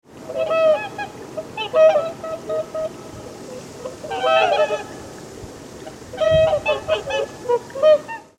So hört sich ein Höckerschwan an: